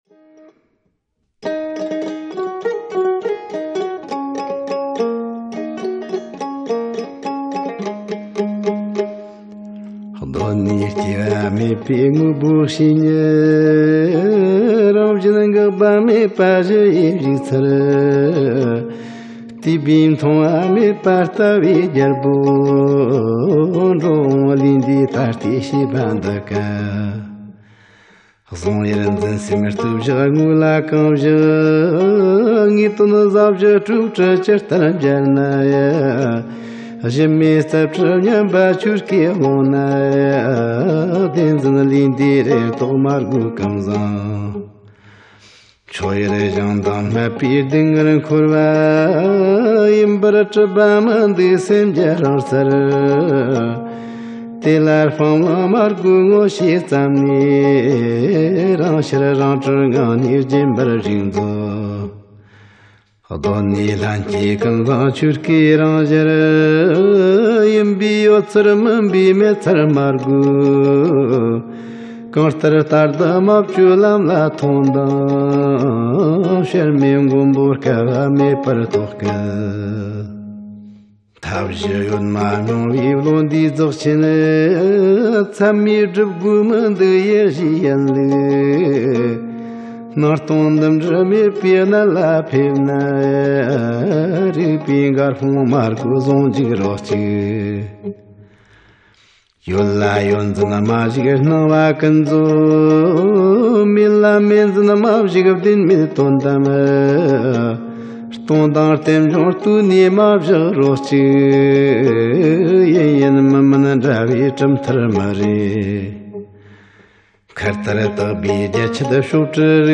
མགུར།